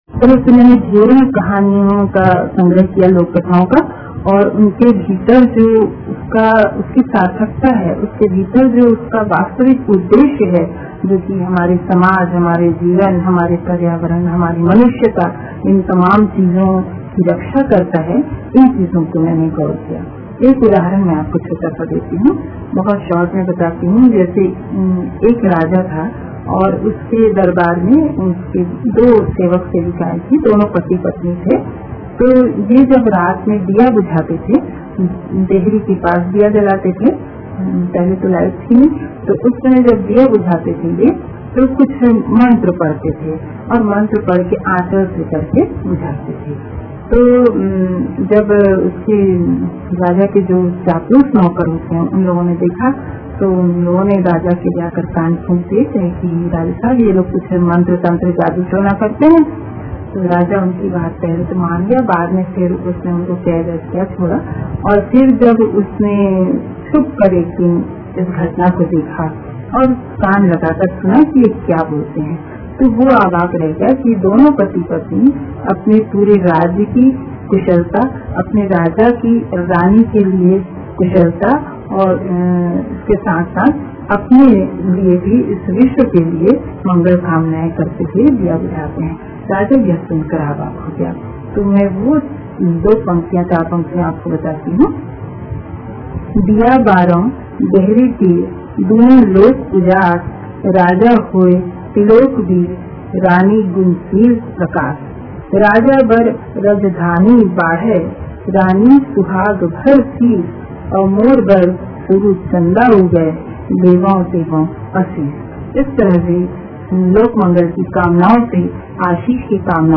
बल्कि गहरा अध्ययन भी की है - उनके साथ एक साक्षातकार